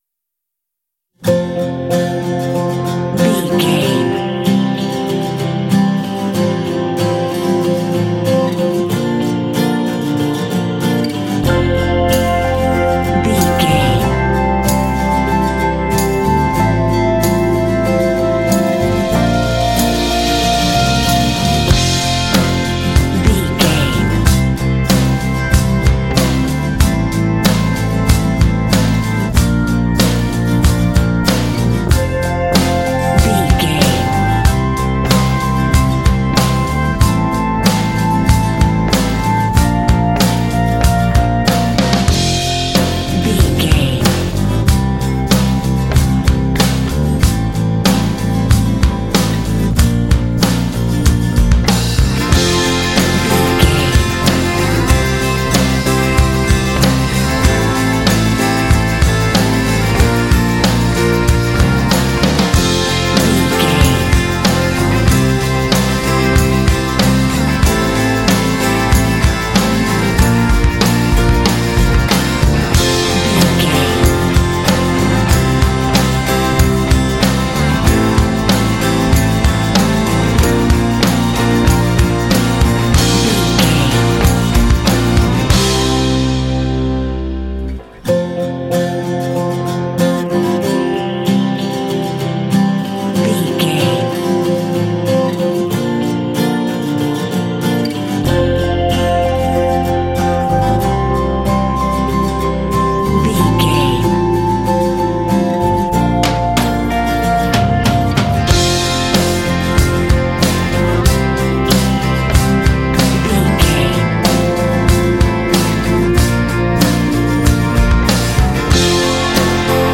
Uplifting
Ionian/Major
optimistic
driving
sentimental
drums
electric guitar
acoustic guitar
bass guitar
synthesiser
electric organ
percussion
rock
pop
alternative rock
indie